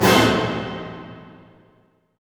Index of /90_sSampleCDs/Roland - String Master Series/HIT_Dynamic Orch/HIT_Orch Hit dim
HIT ORCHD0DR.wav